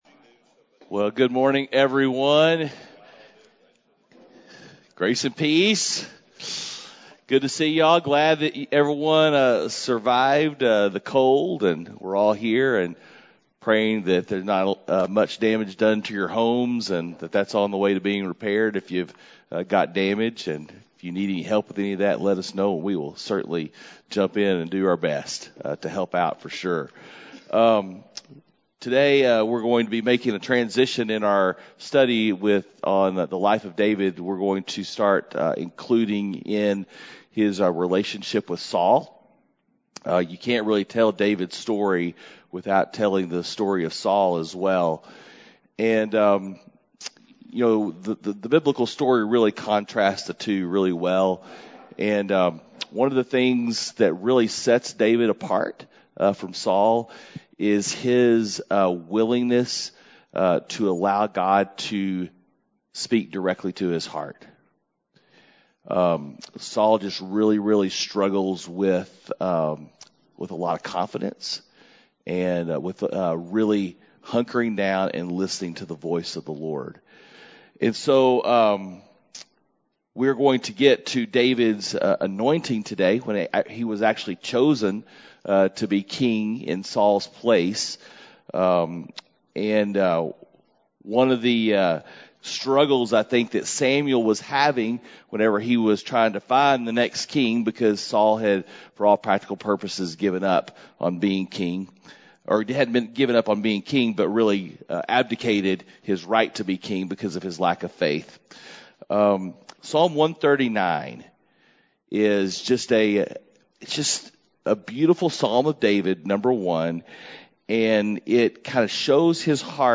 Men’s Breakfast Bible Study 2/23/21
Mens-Breakfast-Bible-Study-2_23_21.mp3